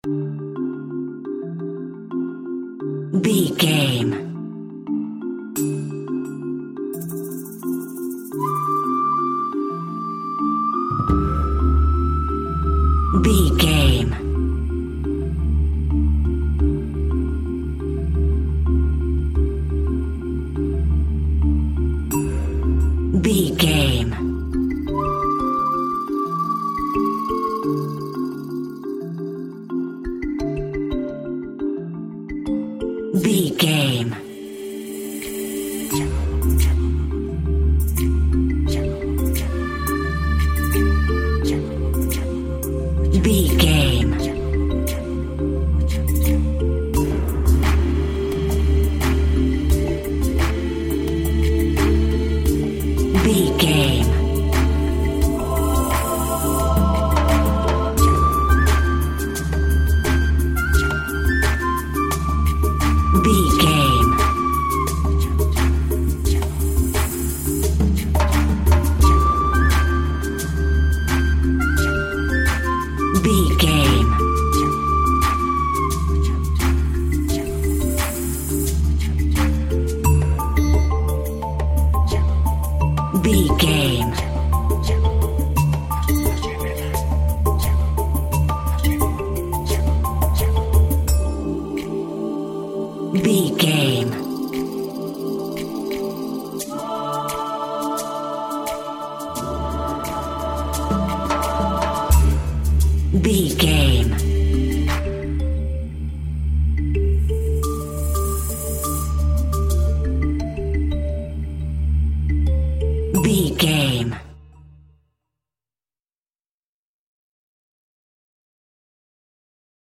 Aeolian/Minor
WHAT’S THE TEMPO OF THE CLIP?
Lounge
chill out
easy listening
laid back
relaxed
nu jazz
downtempo
Exotica
synths
synth lead
synth bass
synth drums